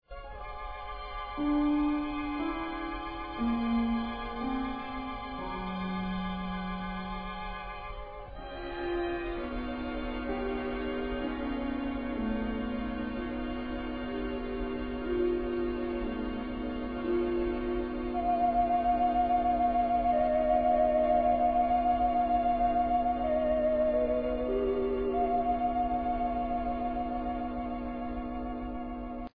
Ηχητική μπάντα παράστασης
sound 29'', track 23, μουσική